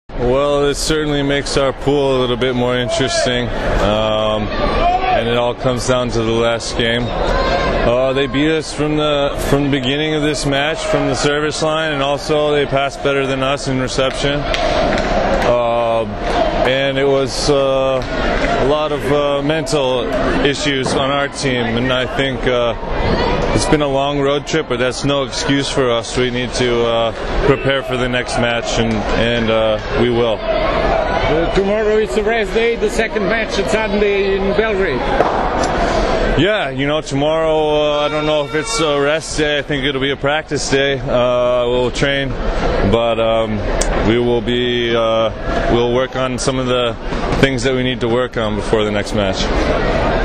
IZJAVA ŠONA RUNIJA